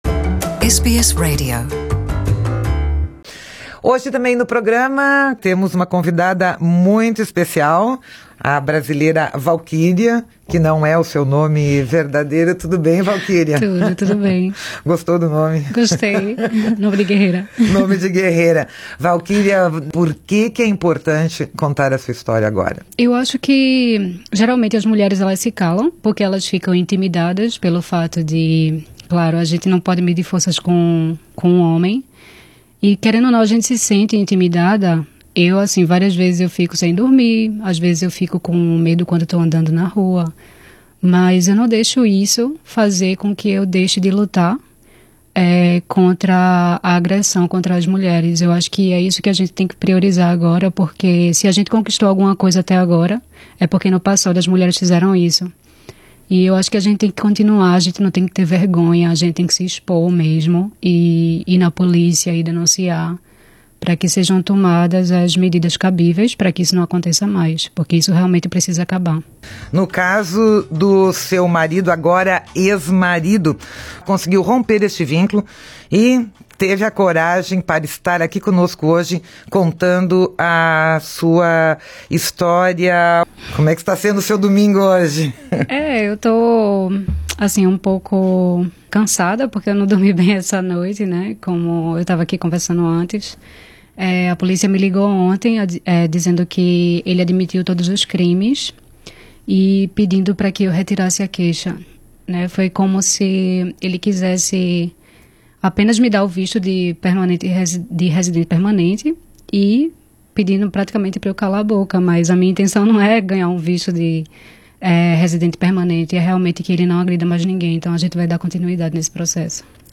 *A pedido da entrevistada seu nome verdadeiro foi omitido nesta reportagem para sua proteção.